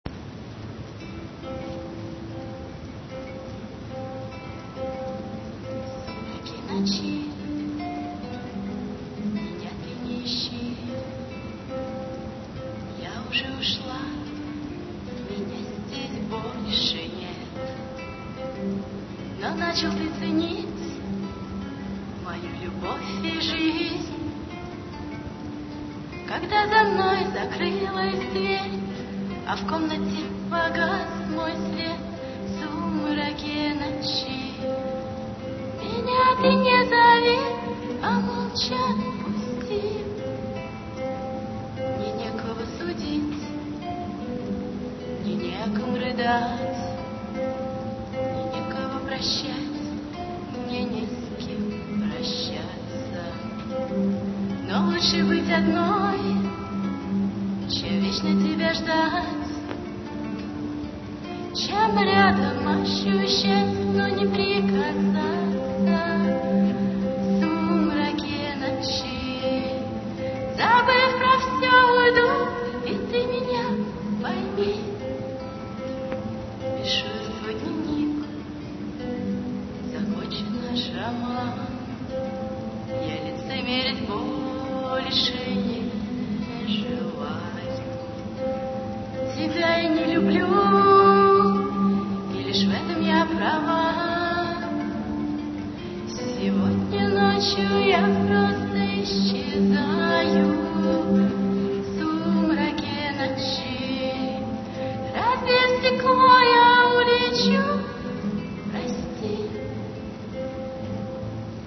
Бардовский концерт
декабрь 2000, Гимназия №45
326 kb, авторское исполнение